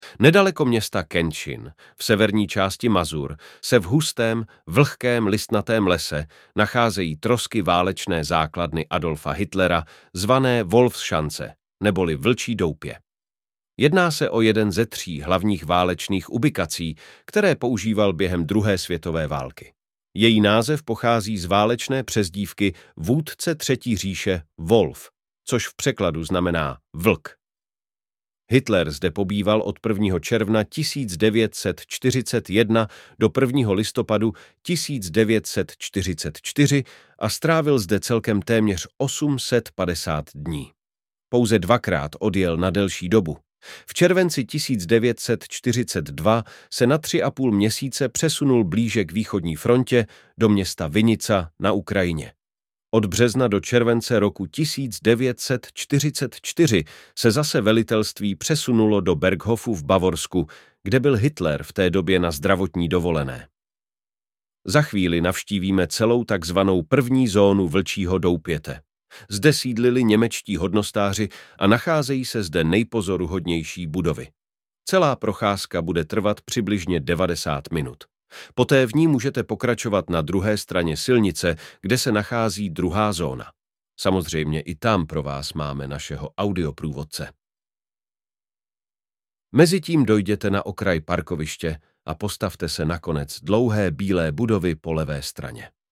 Autorský audioprůvodce po Vlčím doupěti